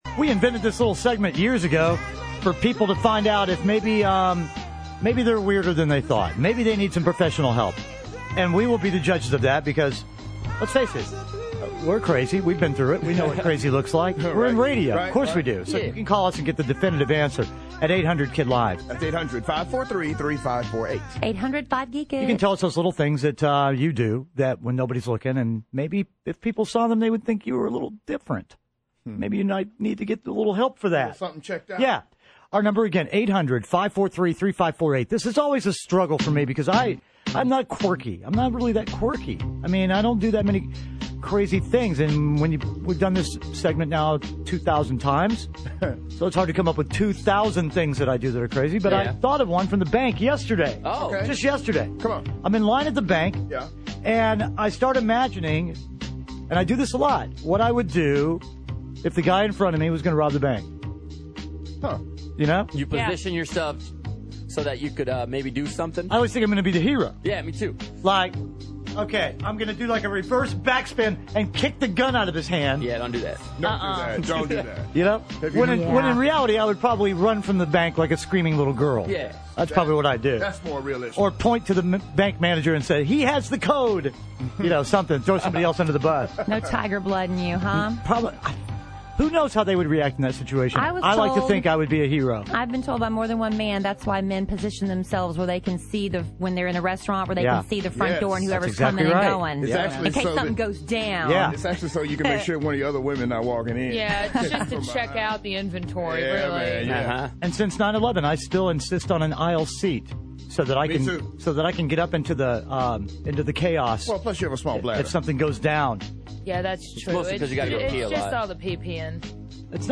Listeners call in to tell us the crazy things they do! But are they really crazy?